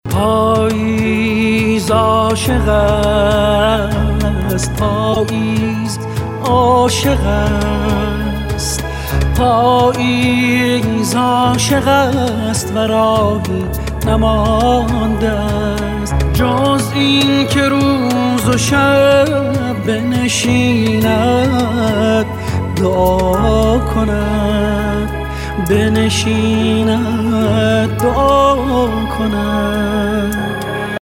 رینگتون ملایم و با کلام